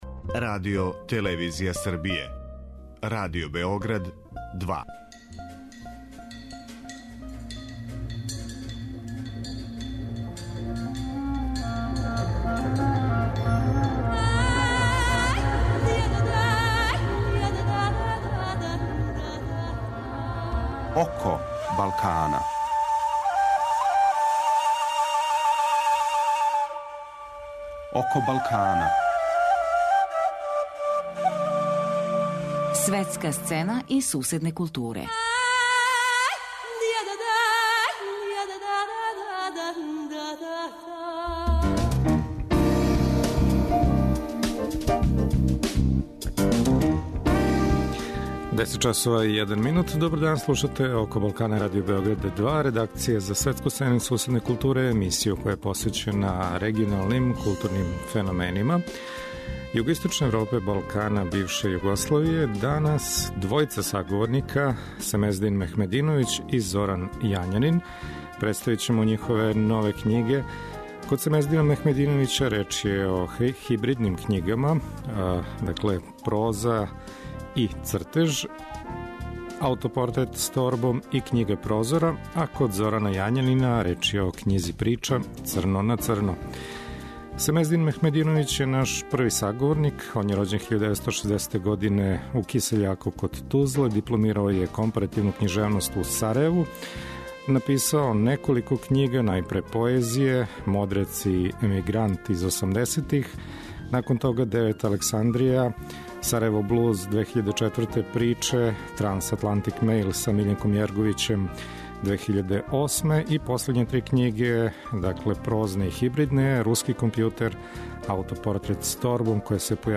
Гост емисије је Семездин Мехмединовић, писац из Босне и Херцеговине који од друге половине 90-их живи у САД.